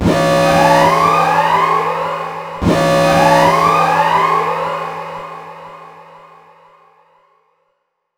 buzzer.wav